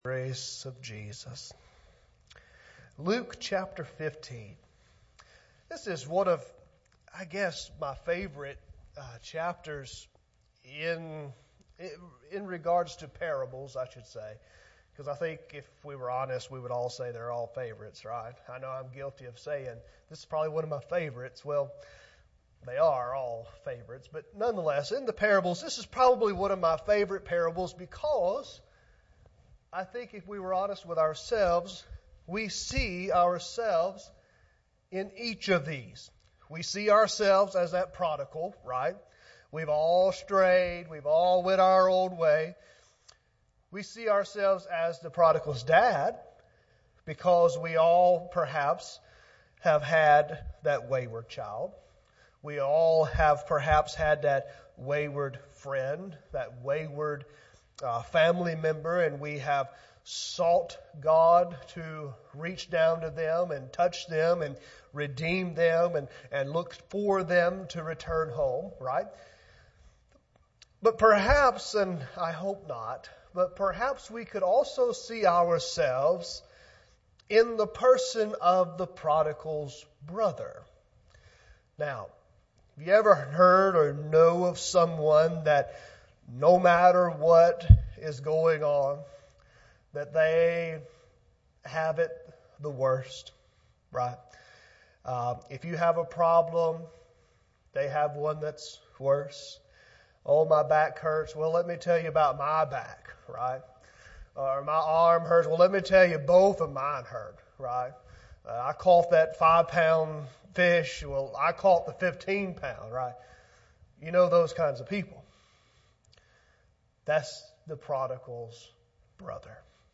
Sermons | West Acres Baptist Church
Guest Speaker